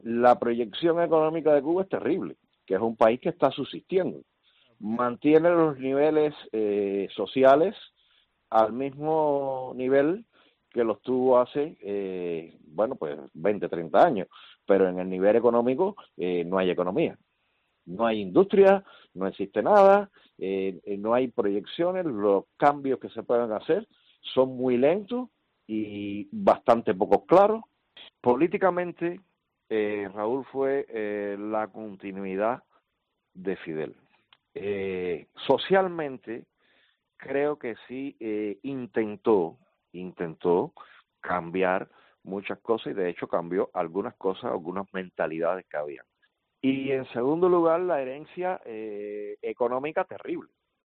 Un ciudadano cubano residente en España, sobre la situación actual